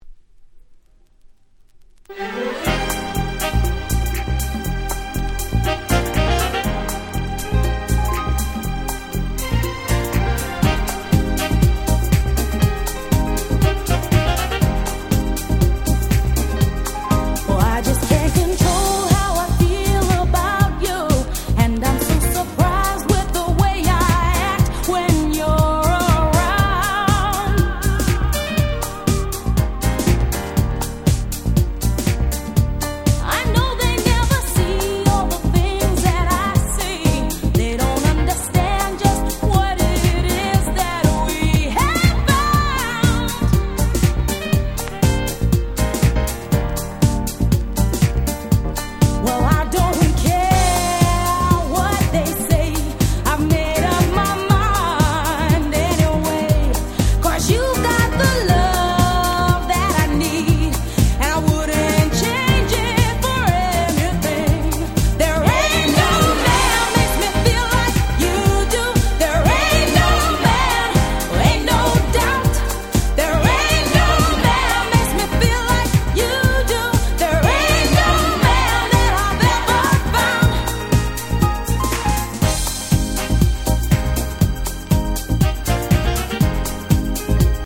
93' Nice R&B Album !!
90's キャッチー系 ボーカルハウス